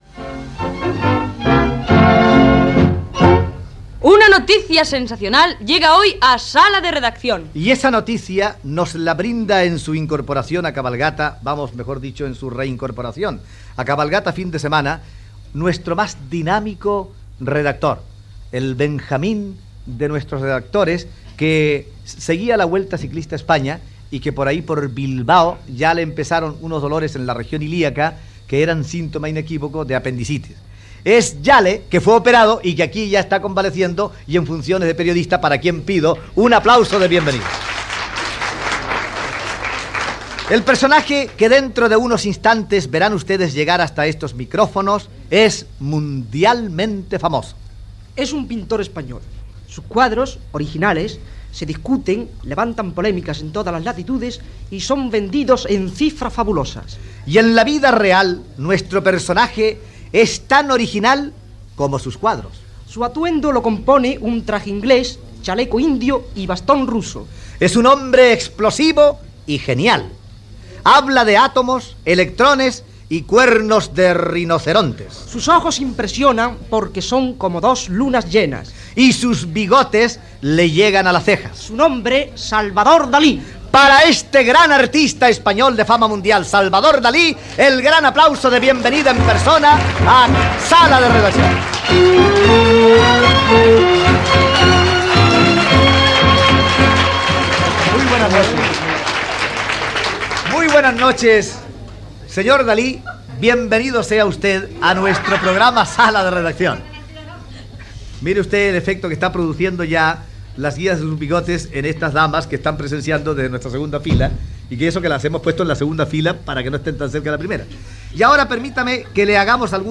Presentació i entrevista al pintor Salvador Dalí